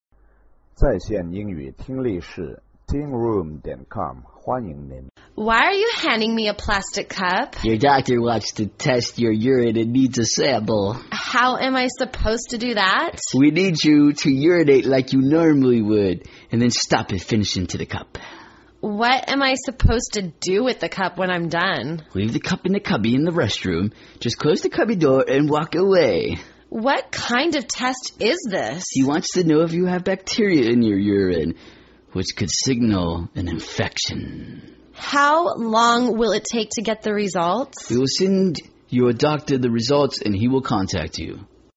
原版英语对话：Taking a Urine Sample(2) 听力文件下载—在线英语听力室